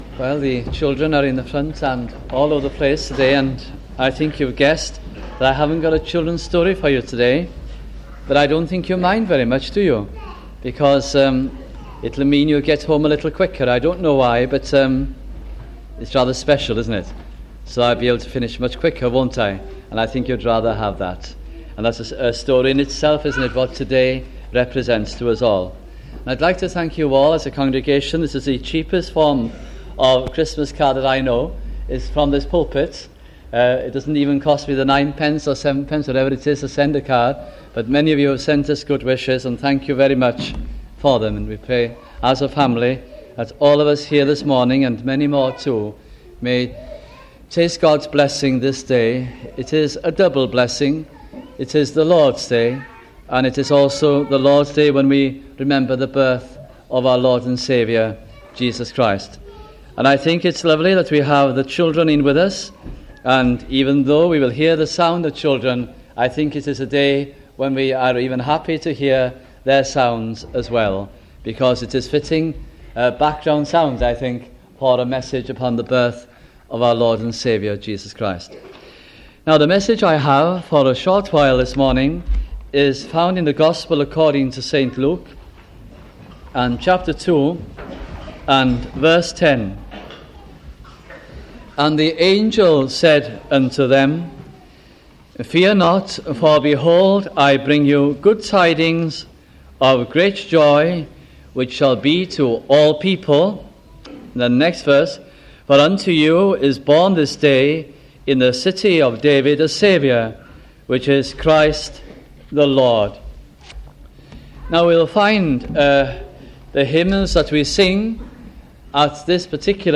Title: Christmas Morning Service 1977